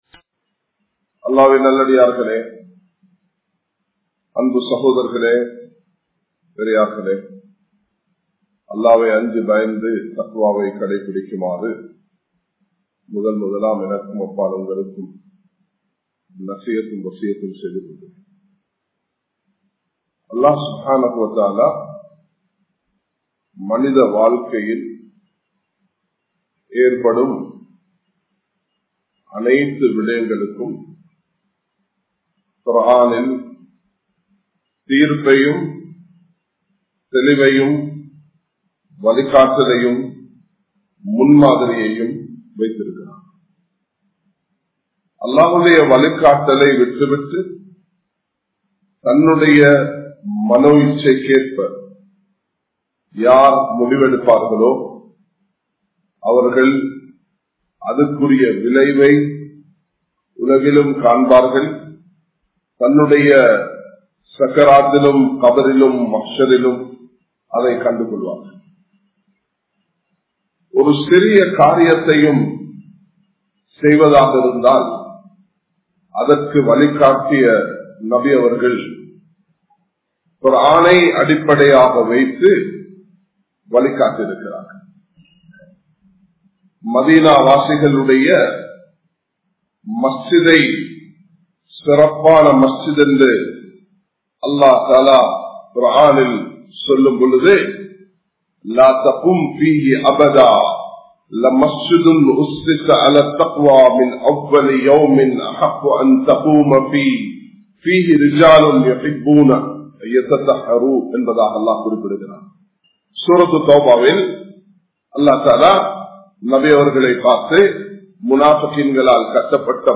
Islam Koorum Thandanai Muraihal(இஸ்லாம் கூறும் தண்டனை முறைகள்) | Audio Bayans | All Ceylon Muslim Youth Community | Addalaichenai
Colombo 11, Samman Kottu Jumua Masjith (Red Masjith)